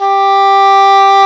Index of /90_sSampleCDs/Roland L-CDX-03 Disk 1/WND_English Horn/WND_Eng Horn 2